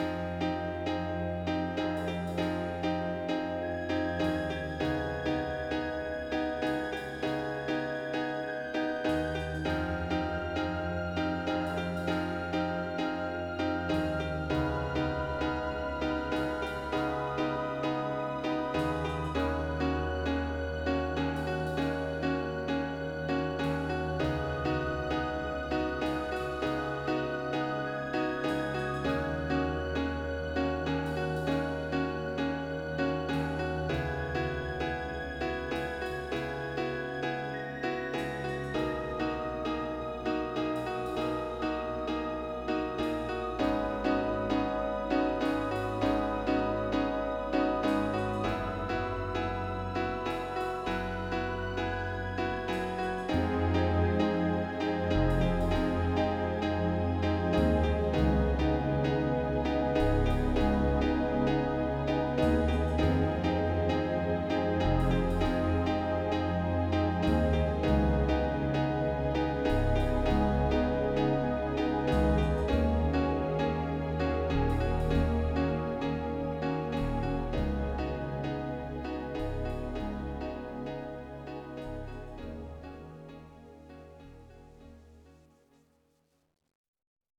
Relaxációs cd 50 perc zenével.